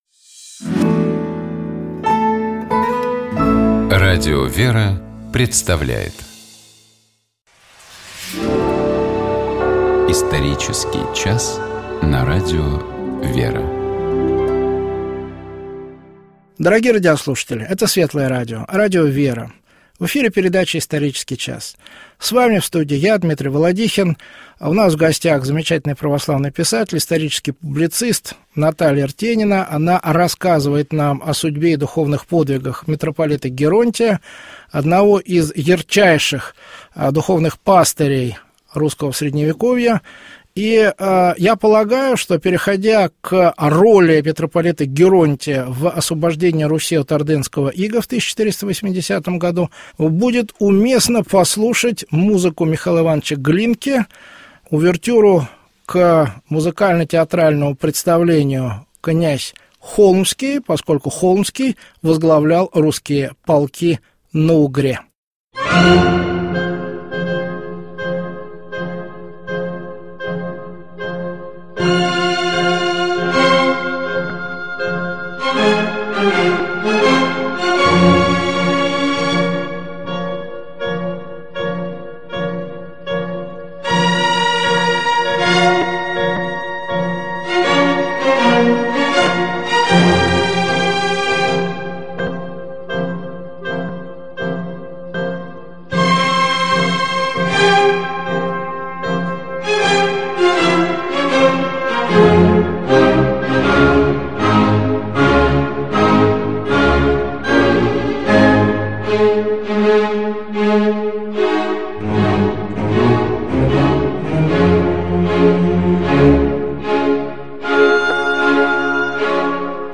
Гость программы: писатель, исторический публицист